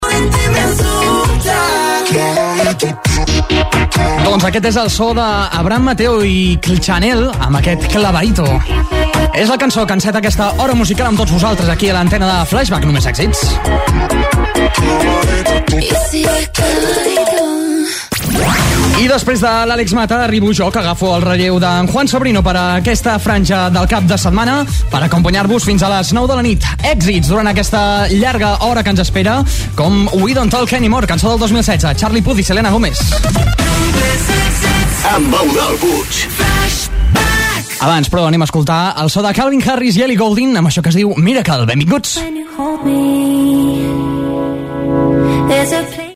Tema musical, indicatiu del programa i tema musical
Musical
FM